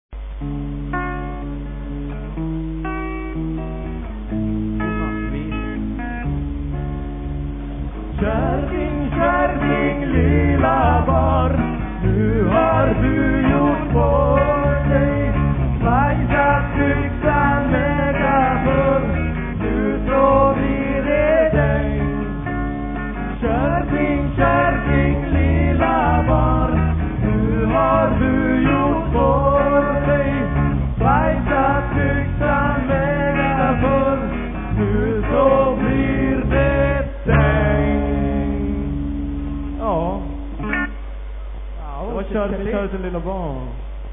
Guitars, Vocals
Gonattvisa